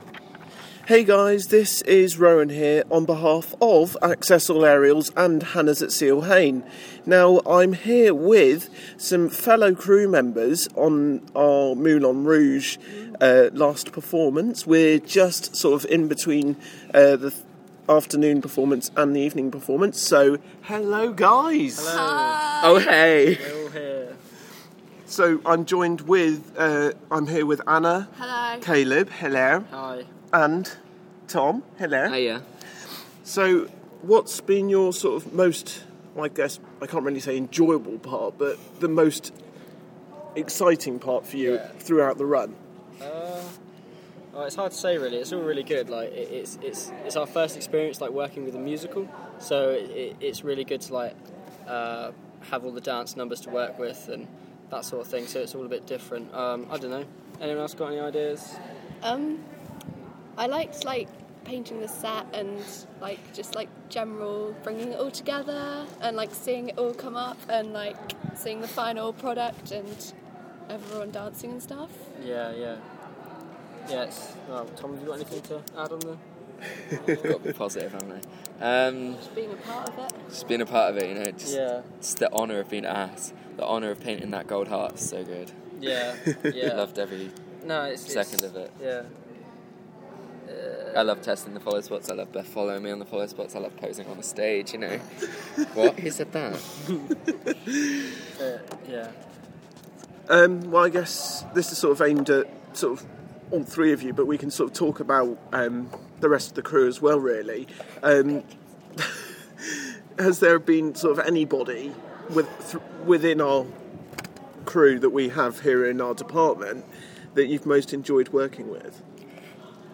Interview with crew